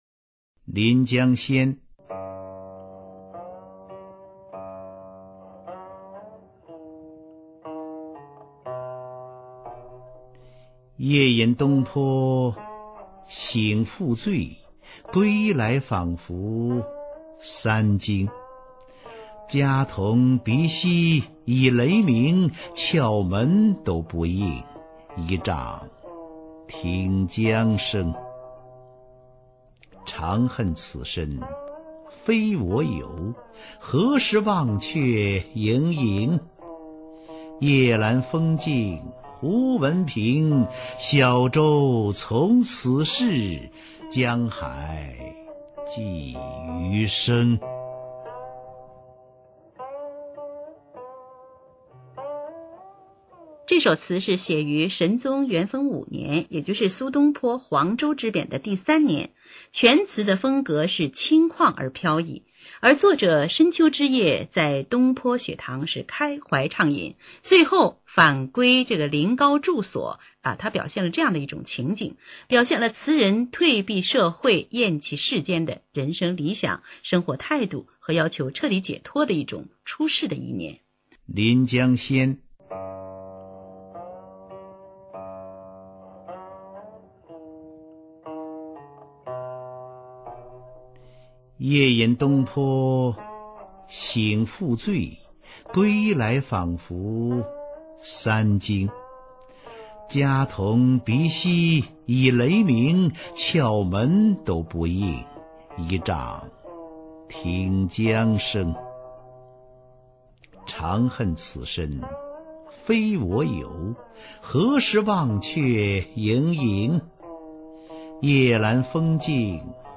[宋代诗词诵读]苏轼-临江仙 宋词朗诵